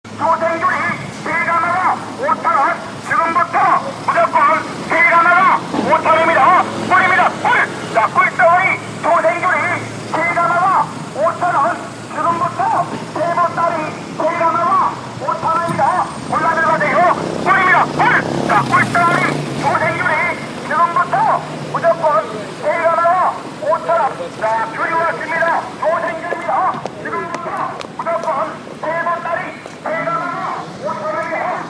(sound) - South Korean fruit seller